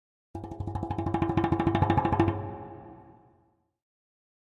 Drums Percussion Danger - Slower Drumming On A Thin Metal Percussion 3